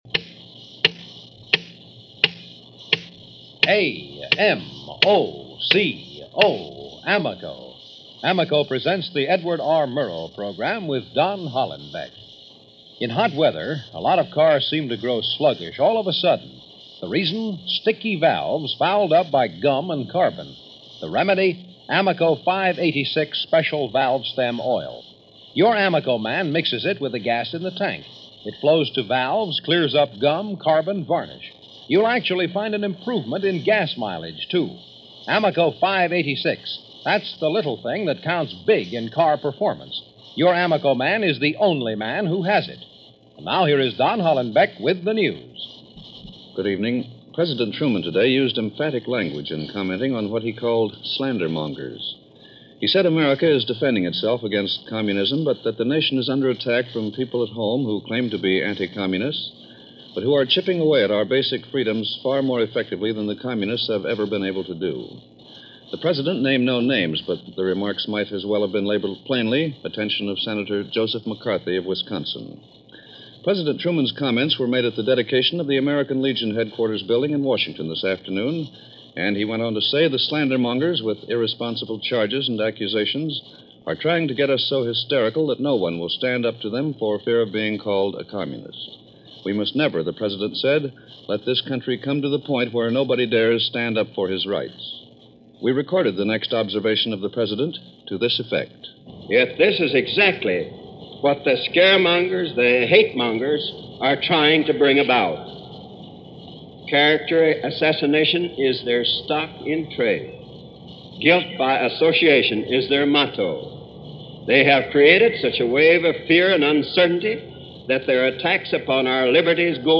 – August 14, 1951 – Don Hollenbeck for Edward R. Murrow and The News –
Truman’s address drew polite applause from the Legionnaires, but was not embraced by them for the most part.